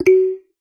marimba_1.wav